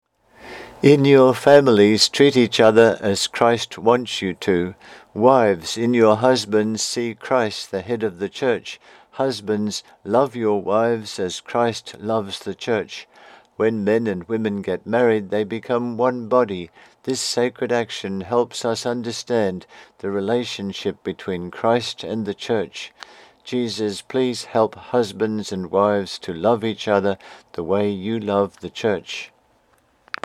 The Book Blog:   4 readings + recordings